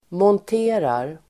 Uttal: [månt'e:rar]
monterar.mp3